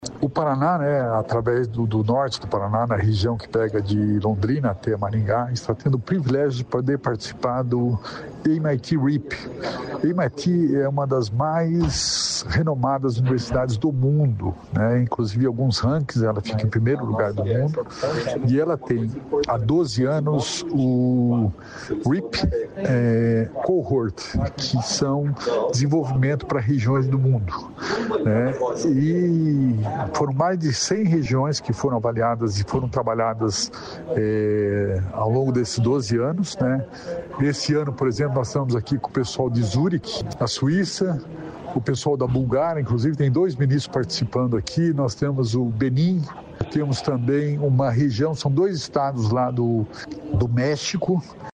Sonora do secretário da Inovação e Inteligência Artificial, Alex Canziani, sobre evento nos EUA